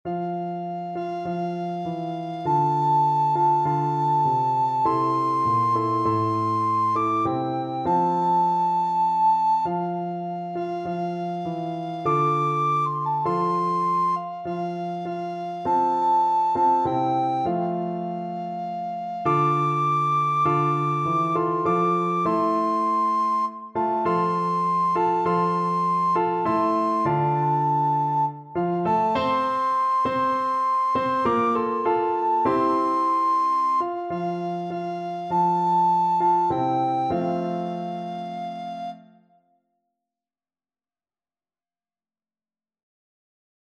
Free Sheet music for Soprano (Descant) Recorder
Steal Away is a spiritual from the African American tradition,
F major (Sounding Pitch) (View more F major Music for Recorder )
4/4 (View more 4/4 Music)
F6-D7
Traditional (View more Traditional Recorder Music)